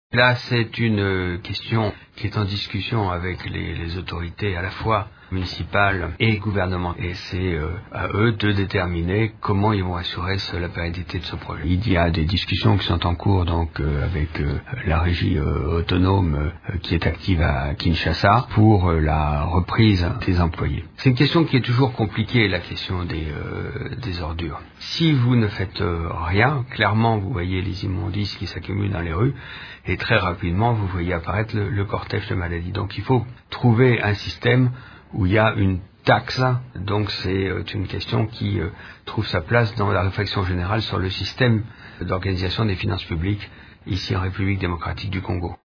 L’ambassadeur de l’Union européenne en RDC, Jean-Michel Dumond, indique que cette question encore en discussion avec les autorités congolaises: